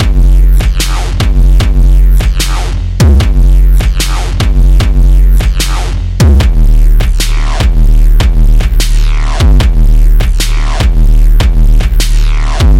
嗡嗡作响的陷阱节拍基础
描述：只是一个简单的蜂鸣式陷阱节拍，非常有效。
Tag: 150 bpm Weird Loops Drum Loops 2.15 MB wav Key : Unknown